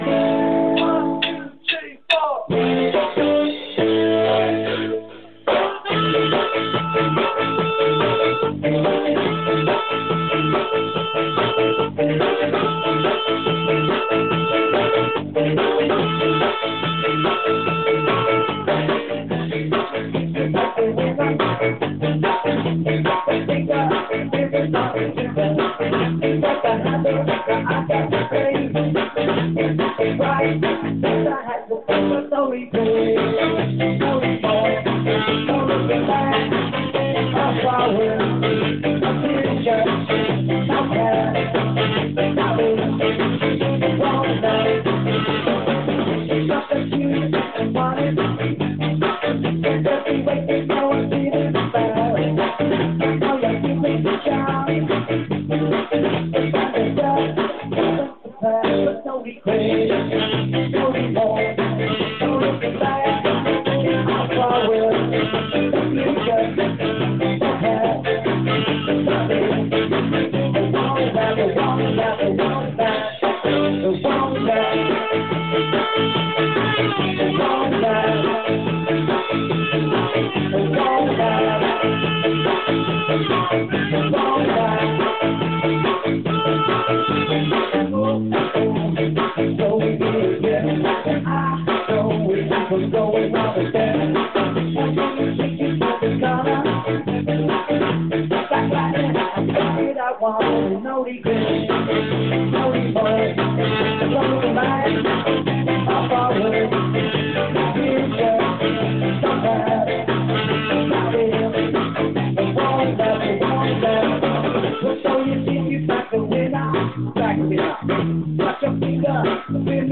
it wont last (rehearsal 19 04 10)